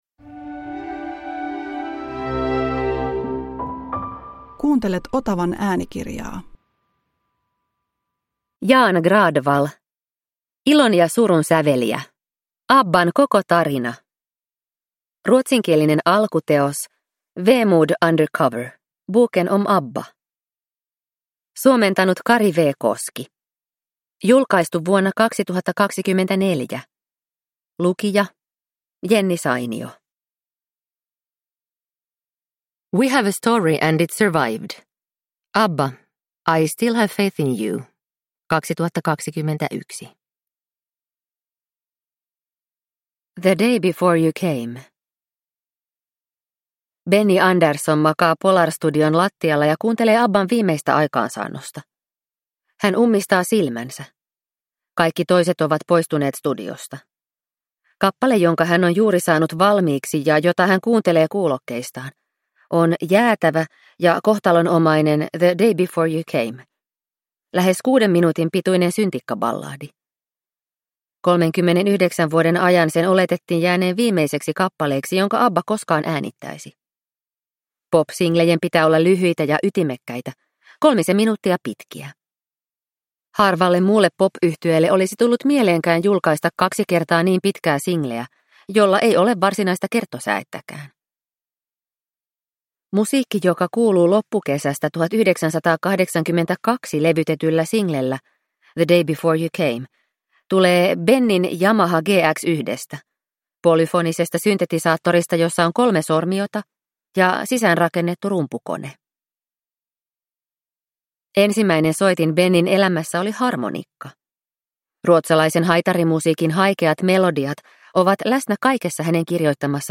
Ilon ja surun säveliä – Ljudbok